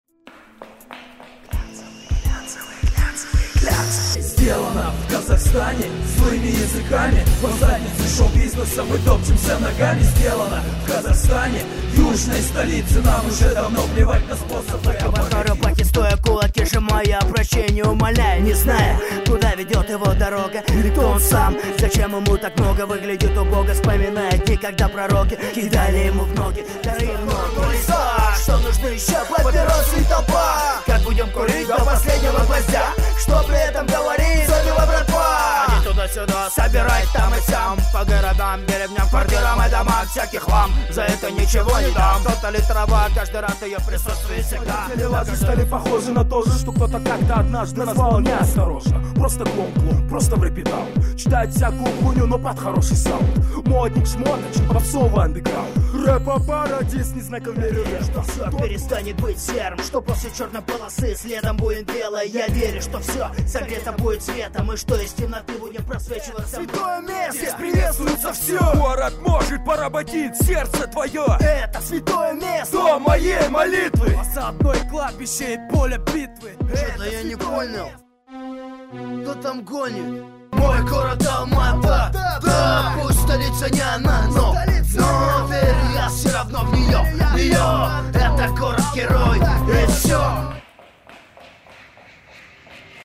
Рэп